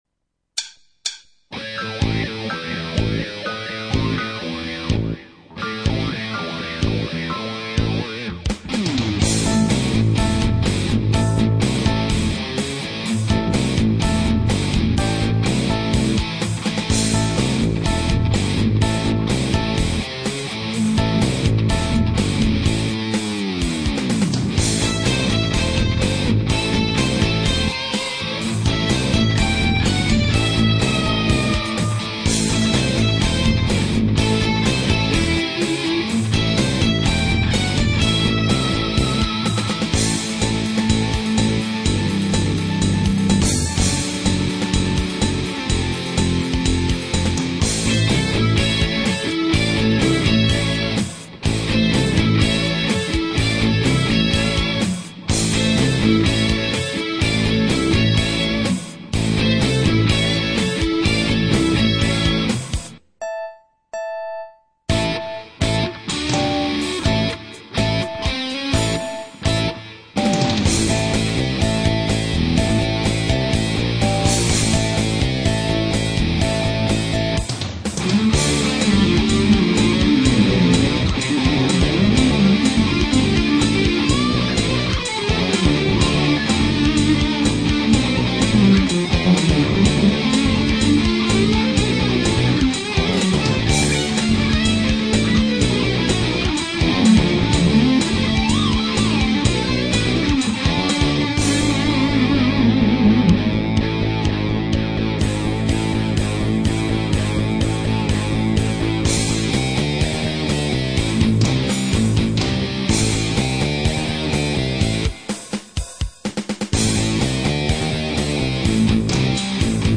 Musica arcade para comemorar ; )